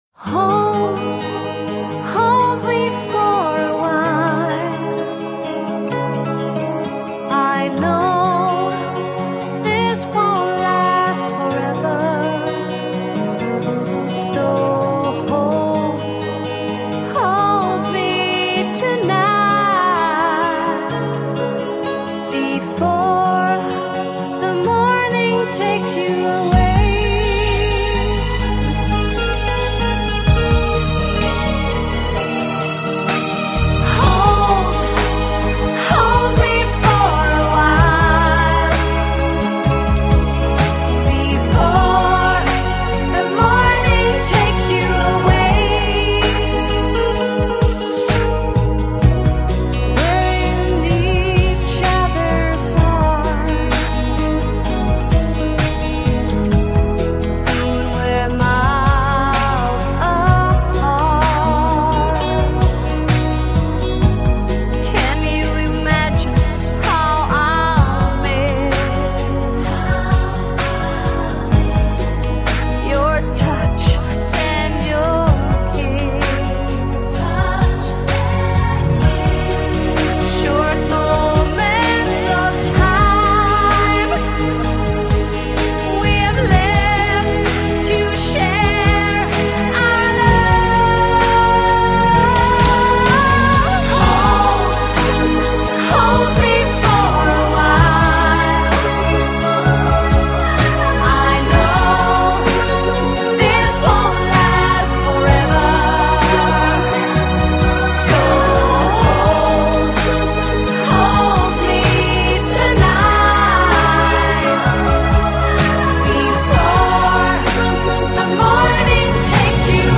Только у него в стиле "еврореггей", а у шведов - баллада.